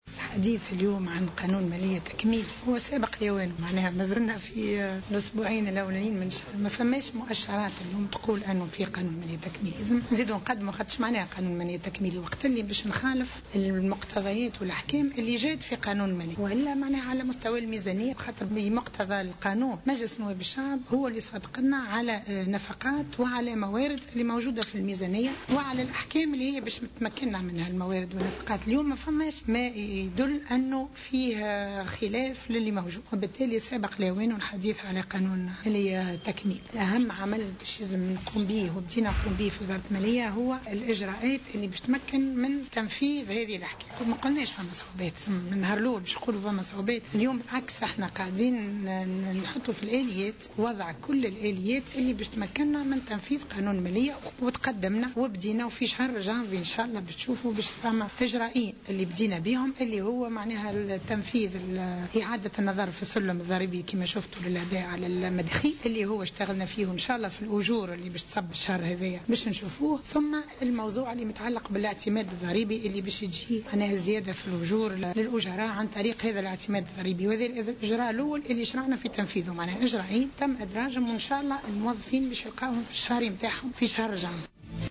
و أضافت على هامش حضورها في ملتقى حول "قانون المالية لسنة 2017" أن الحديث عن قانون مالية تكميلي سابق لأوانه وأنه لا جود لمؤشرات حاليا حول إعداد مشروع مالية تكميلي لسنة 2017.